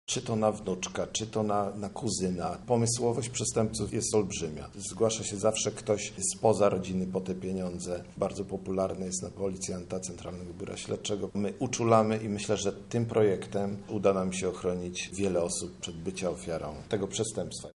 Nie daj się oszukać, oszustom stop  – mówi komendant wojewódzki Paweł Dobrodziej